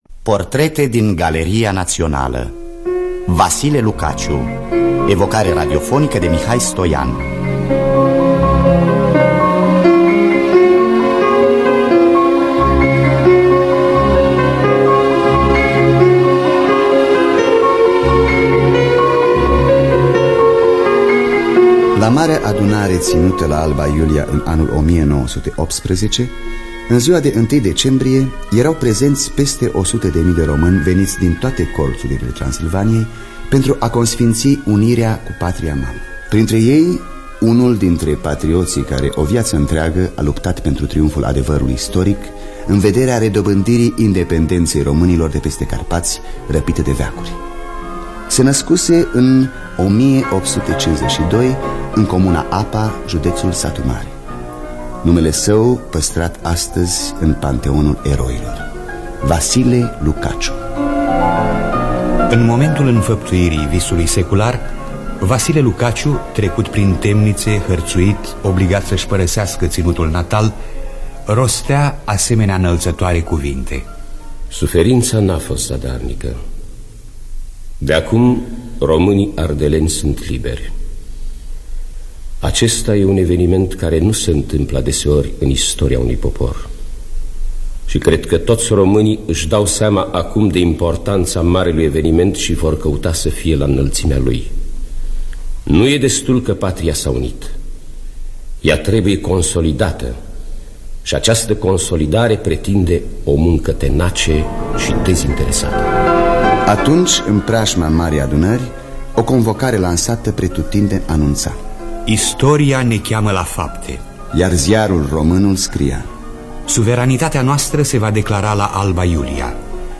Biografii, Memorii: Vasile Lucaciu (1978) – Teatru Radiofonic Online